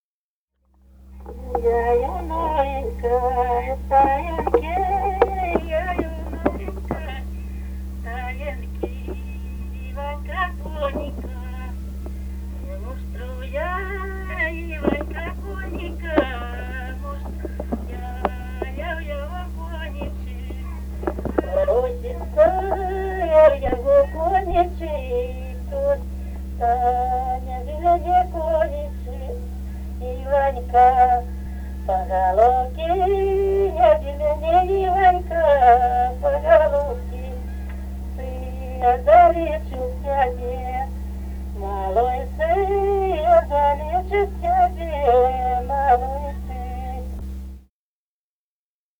Русские народные песни Красноярского края.
«Ай, у новенькой стаинки» (свадебная). с. Дзержинское Дзержинского района.